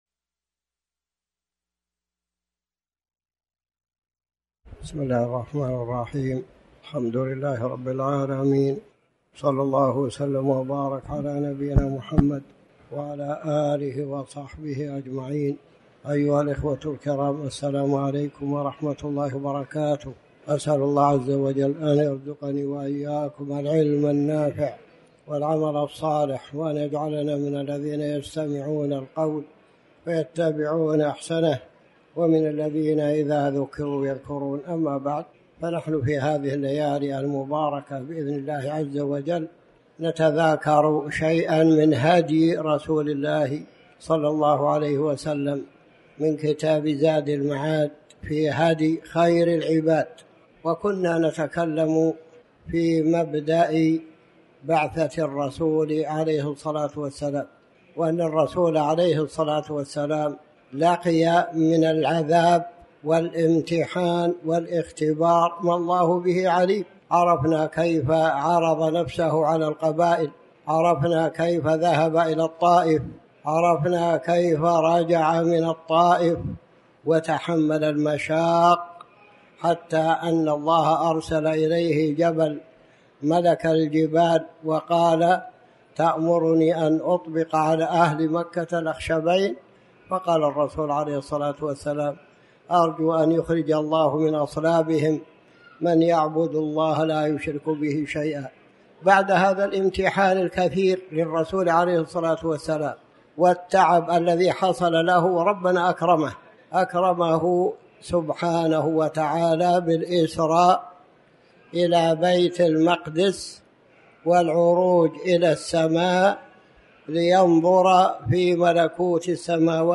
تاريخ النشر ٢٦ ذو الحجة ١٤٤٠ هـ المكان: المسجد الحرام الشيخ